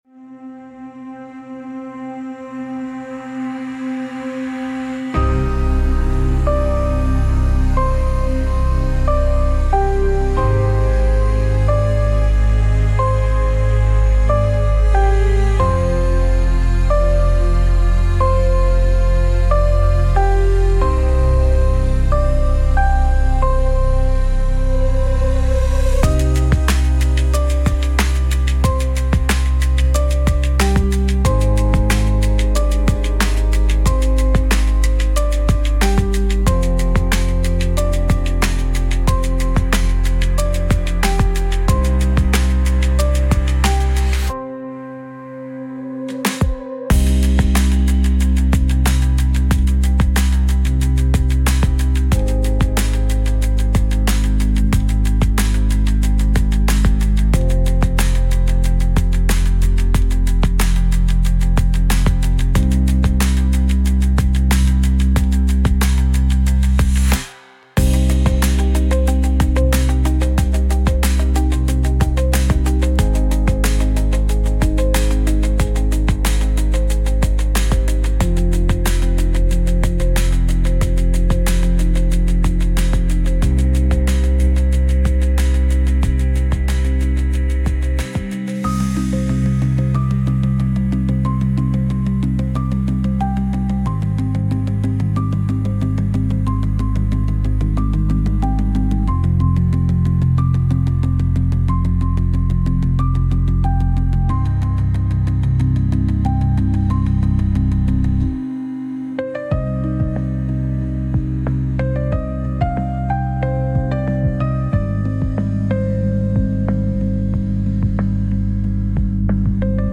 Instrumental- Drift Through the Hollow-4.00 mins